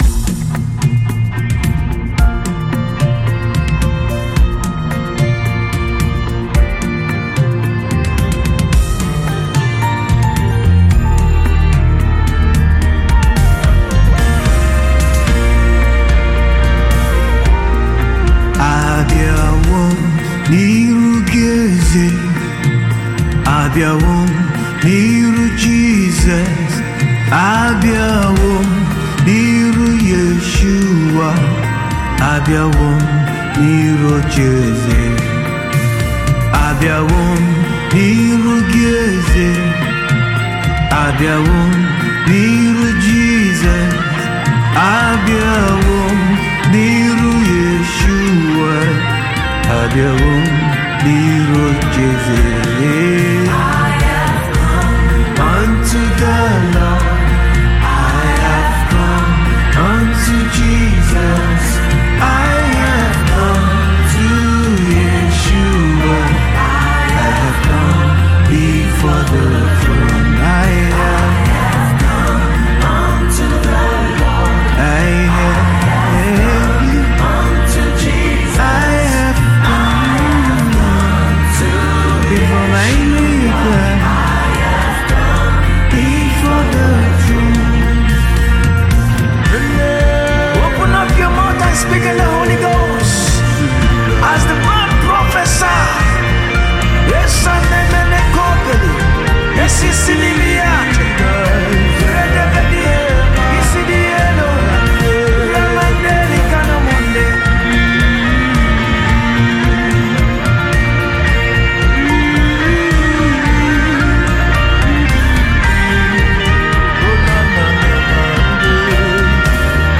contemporary gospel worship song
uplifting yet reflective sound